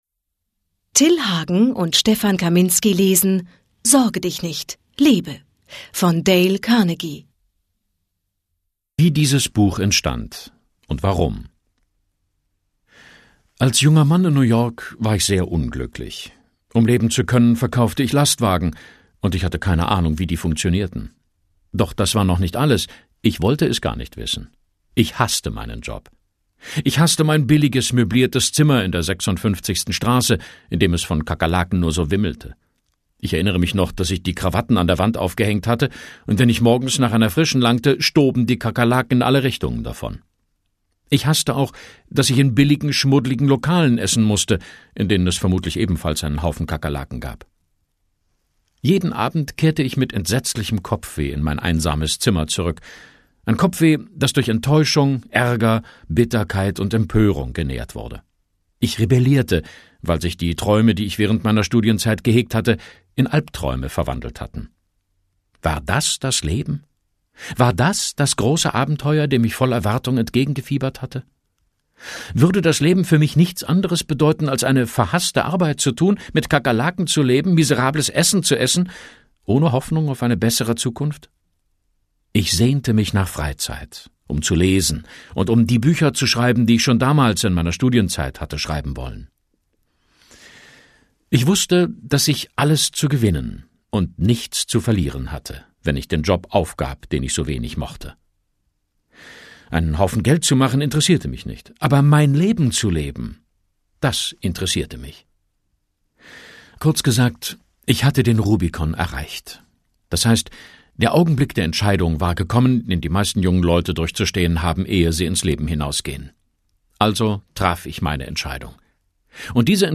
Hörbuch: Sorge dich nicht – lebe!